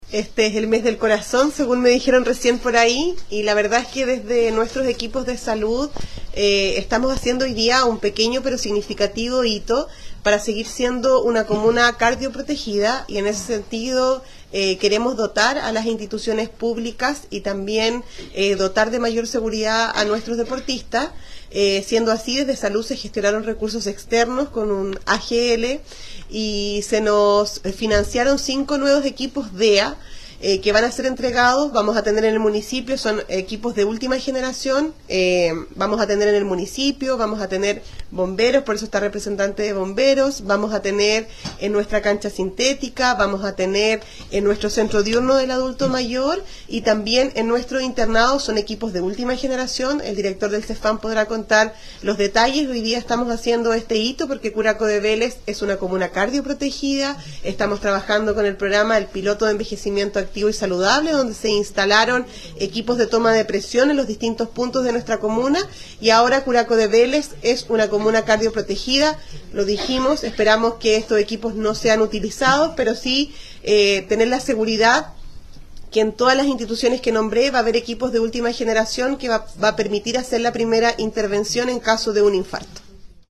ALCALDESA-EQUIPOS-DEA-.mp3